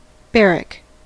barrack: Wikimedia Commons US English Pronunciations
En-us-barrack.WAV